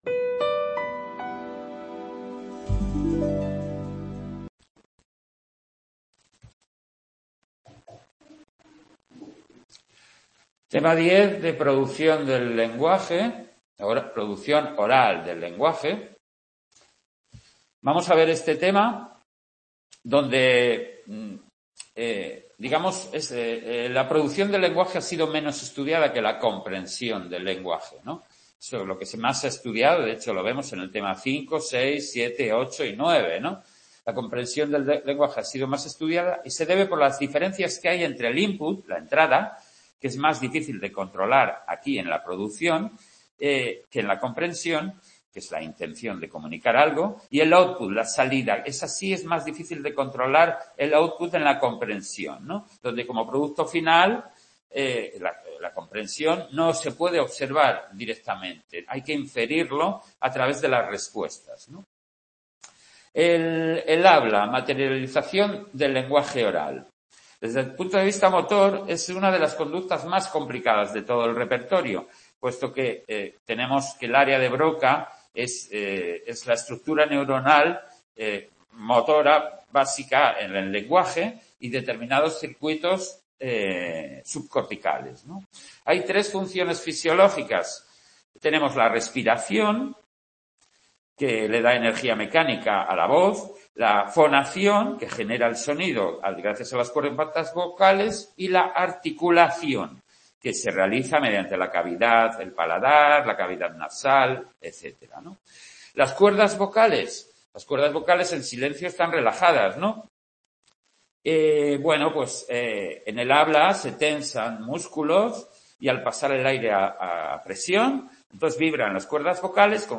en el Centro Asociado UNED de Sant Boi de Llobregat